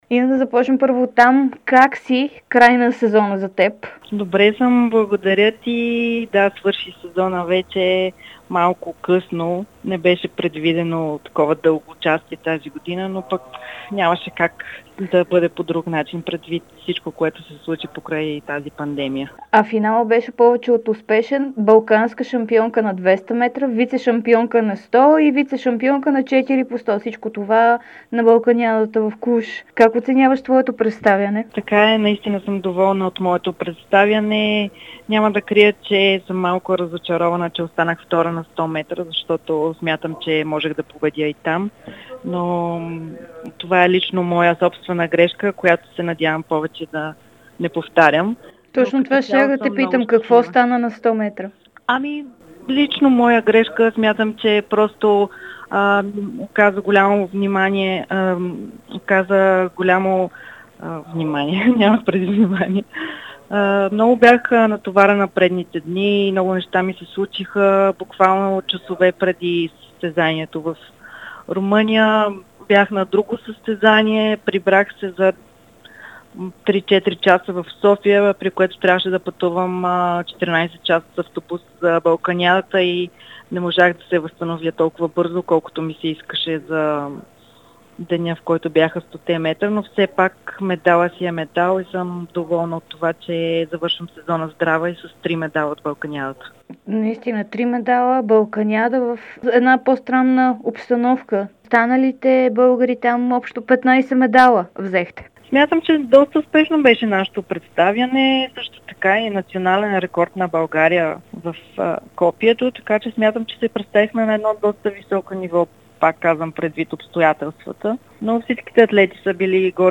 Българската лекоатлетка Инна Ефтимова даде специално интервю за Дарик радио и dsport. Състезателката се завърна от Балканиадата в Клуж с три медала – един златен и два сребърни.